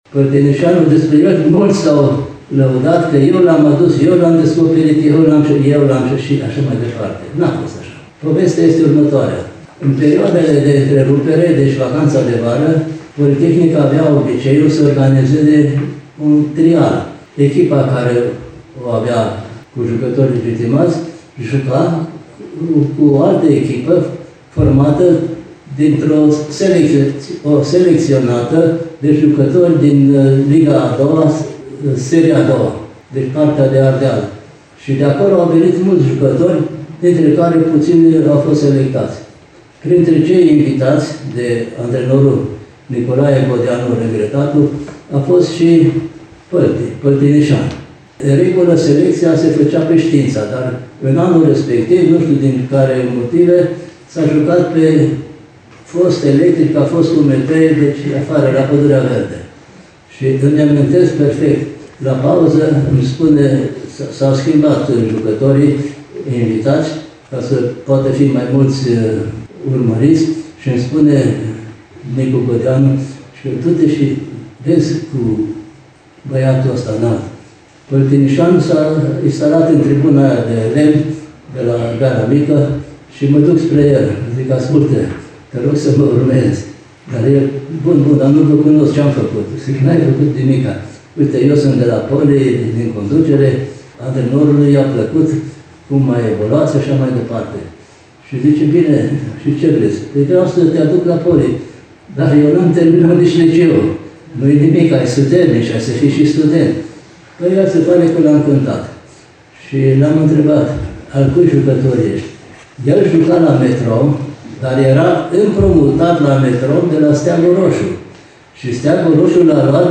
Biblioteca Universității Politehnica Timișoara a reunit astăzi câteva dintre gloriile lui Poli, la aniversarea de 100 de ani a clubului.